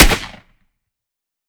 12ga Pump Shotgun - Gunshot A 003.wav